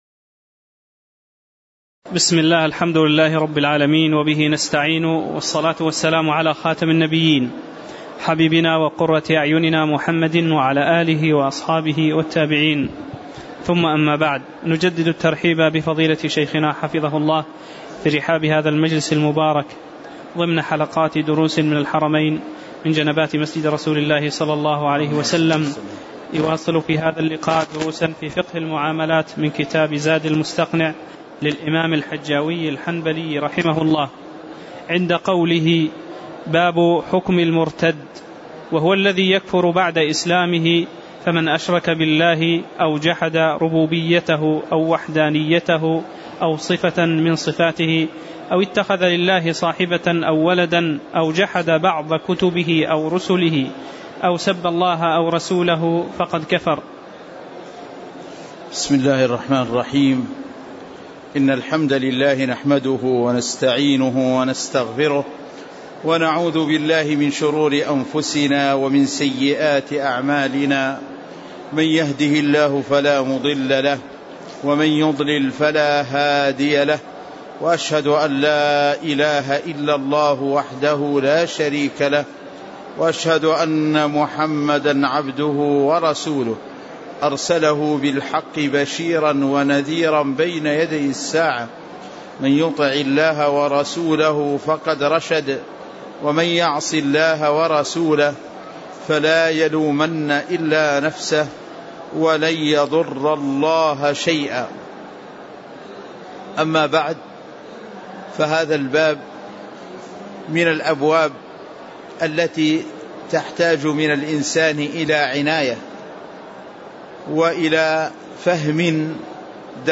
تاريخ النشر ١ جمادى الآخرة ١٤٣٨ هـ المكان: المسجد النبوي الشيخ